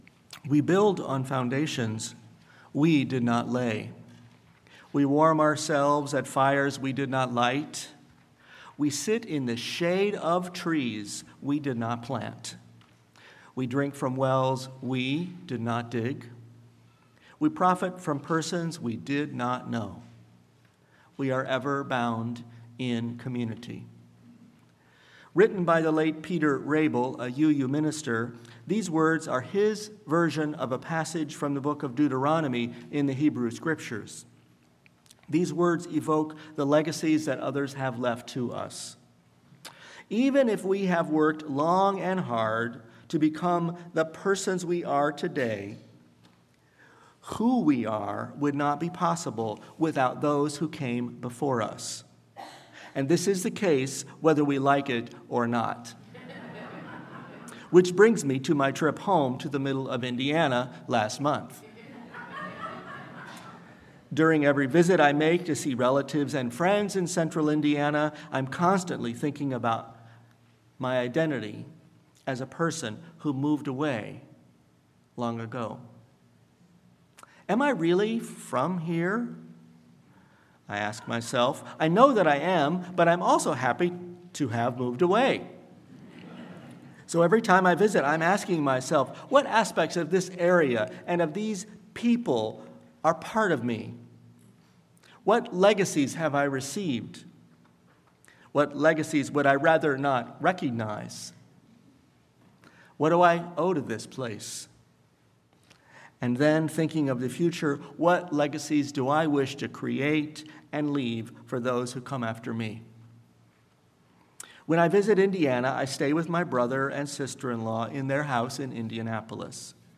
Sermon-Legacies.mp3